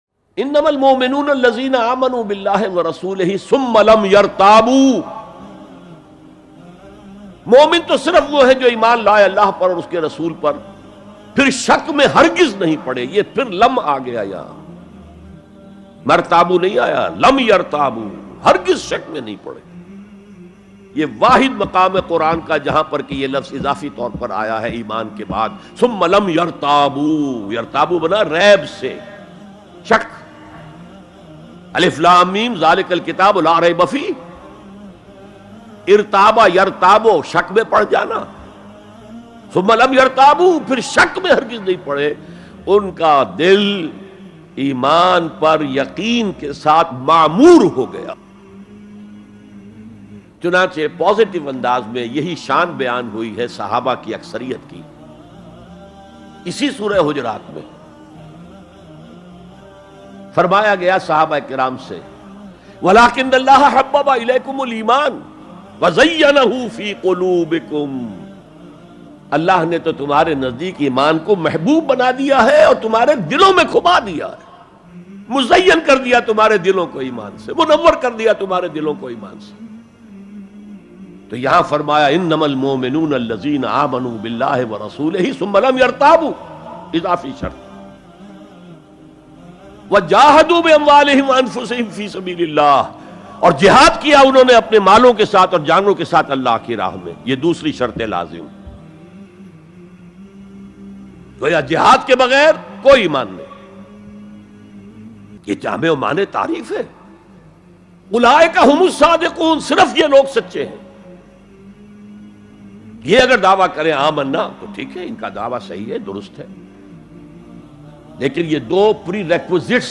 Momin Kon Hai By Dr Israr Amed Very Beautiful Bayan MP3 Download